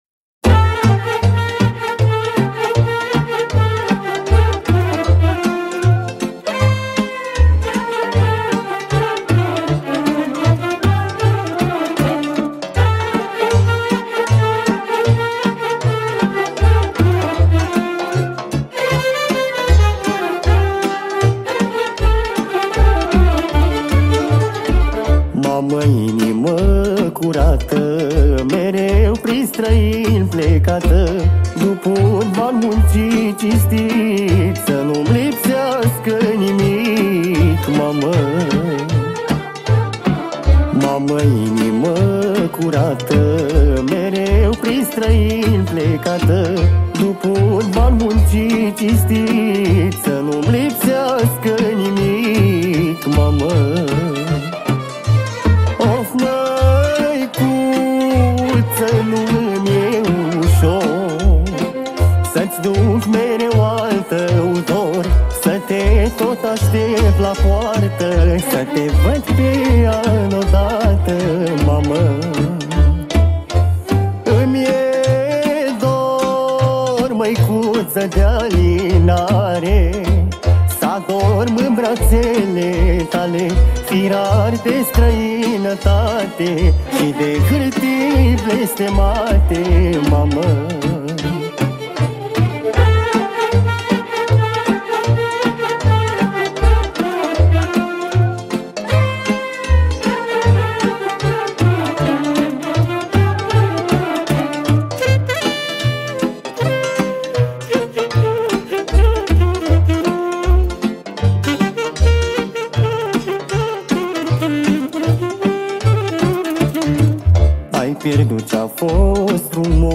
Data: 21.10.2024  Petrecere New Hits: 0